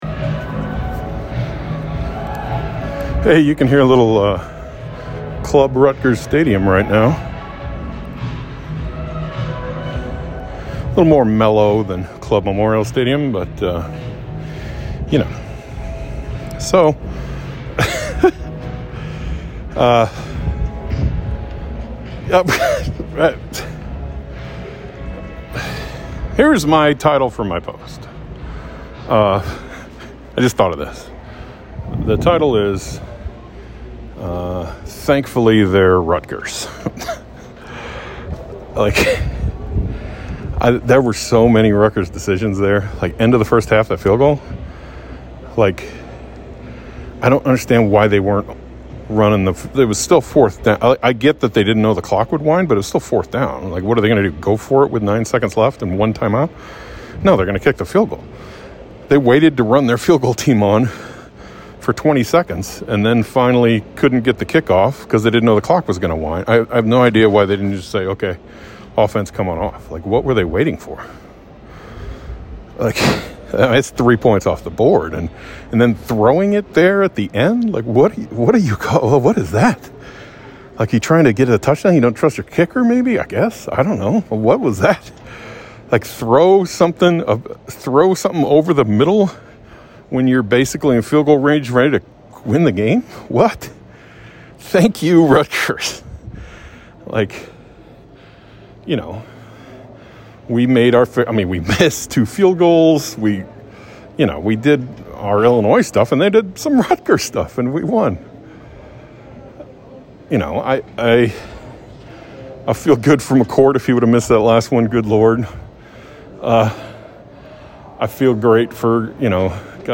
Each Illini football game, I record my immediate thoughts and upload them to the feed.